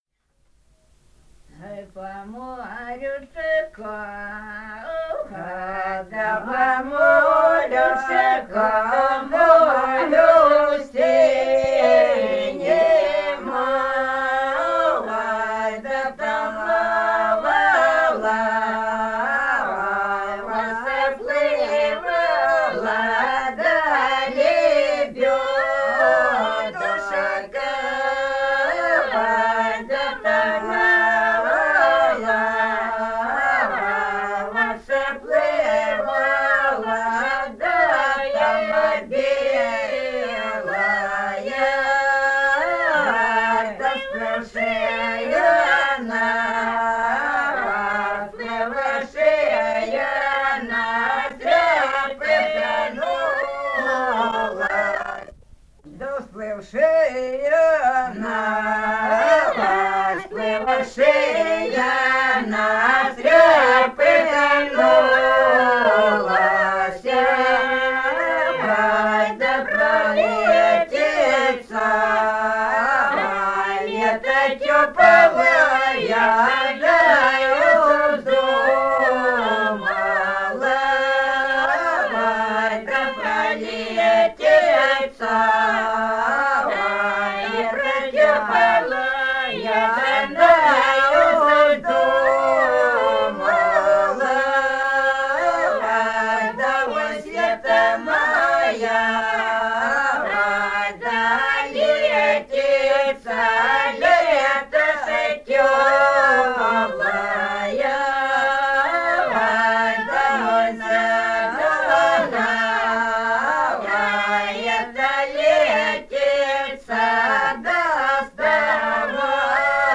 Этномузыкологические исследования и полевые материалы
Ростовская область, г. Белая Калитва, 1966 г. И0941-09